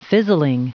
Prononciation du mot fizzling en anglais (fichier audio)
Prononciation du mot : fizzling